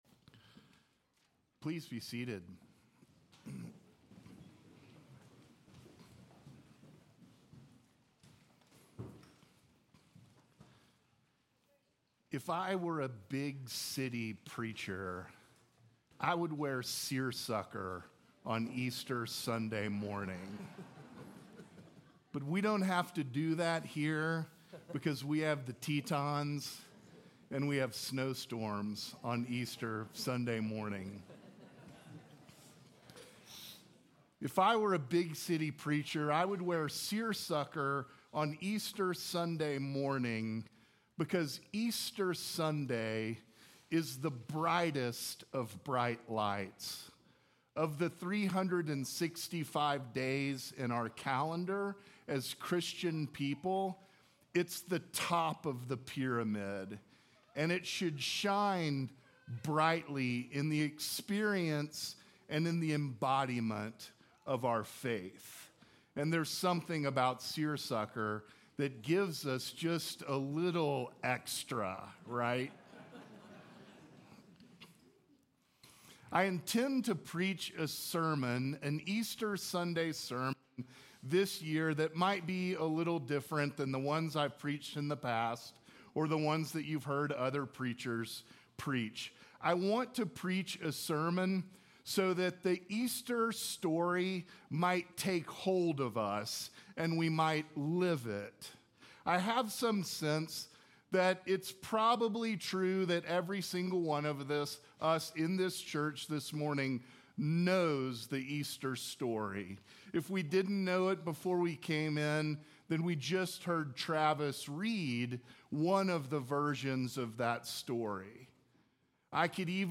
Sermons from St. John's Episcopal Church Easter Sunday